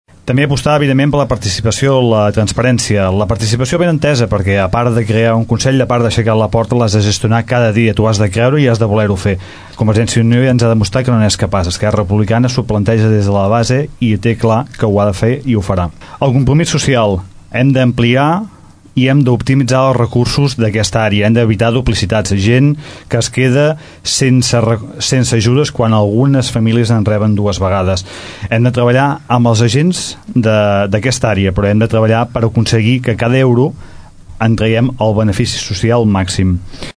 debat erc+gent tordera
debat-erc-gent-tordera.mp3